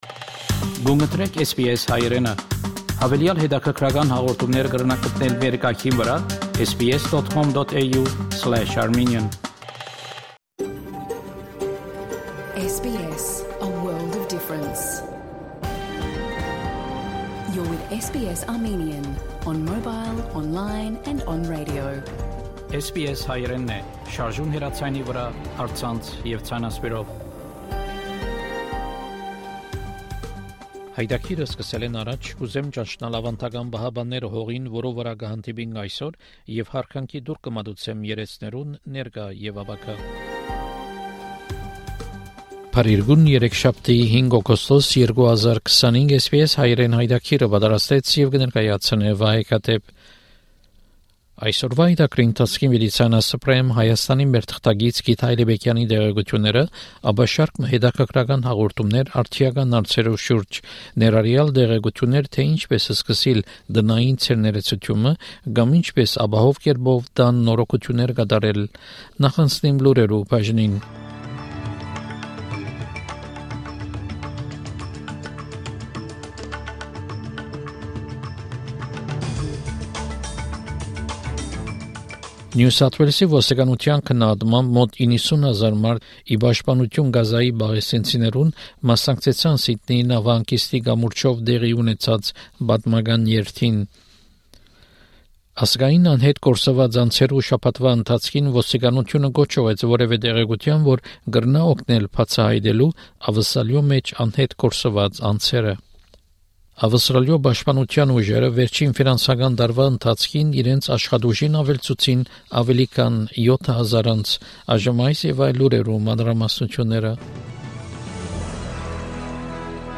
SBS Armenian news bulletin from 5 August 2025 program.